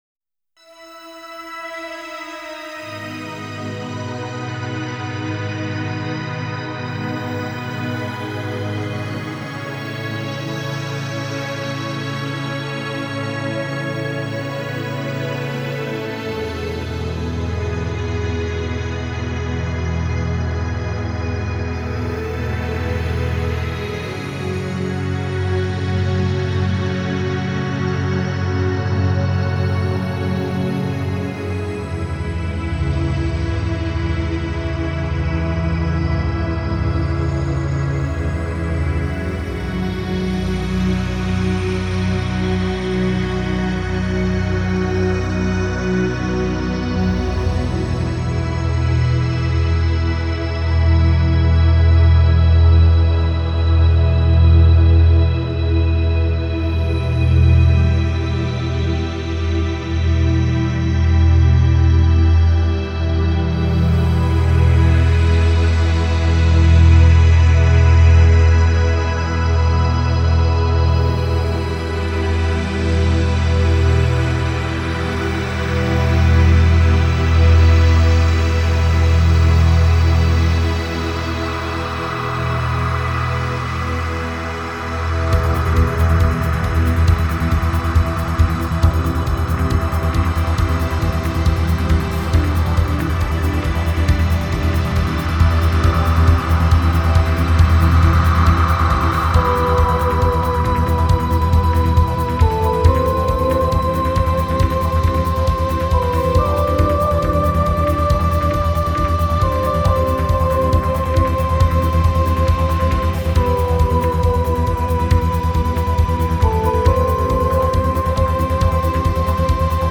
和太鼓協力／西野太鼓製作本店